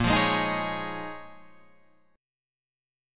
A#m11 chord